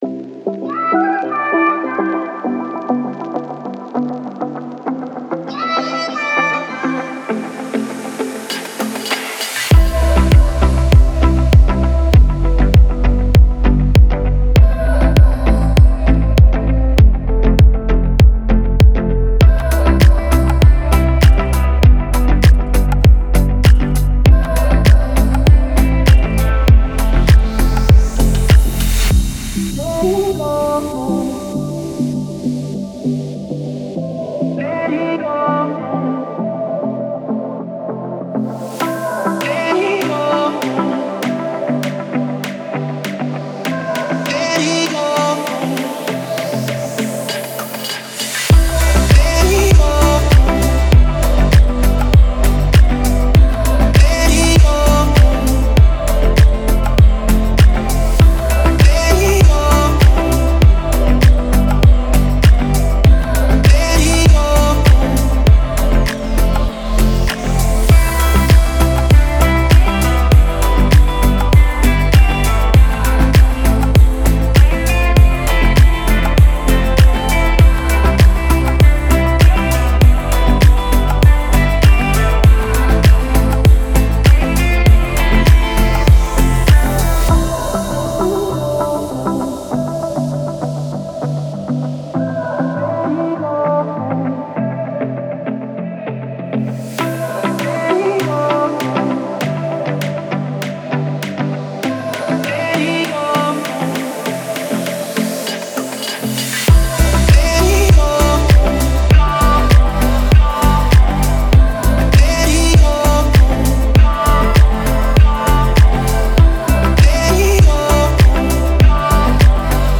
мелодичная электронная композиция